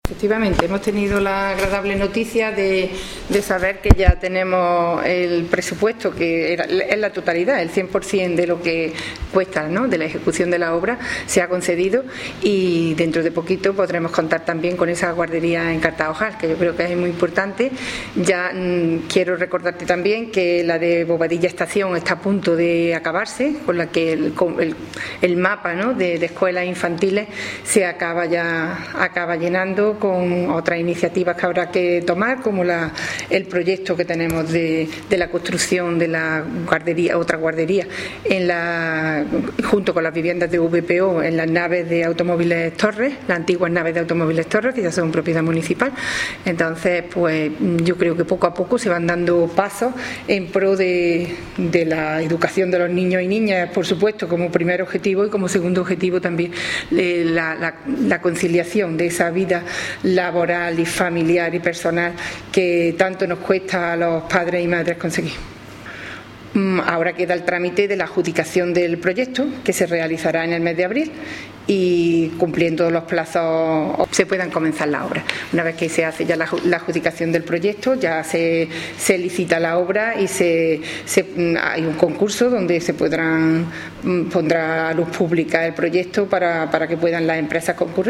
Cortes de voz
Audio: concejala de Educación   1376.73 kb  Formato:  mp3